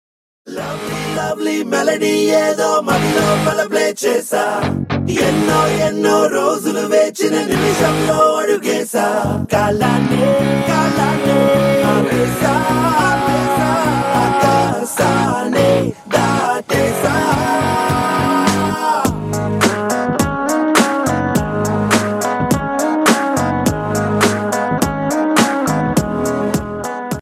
south flute ringtone download | love song ringtone
romantic ringtone download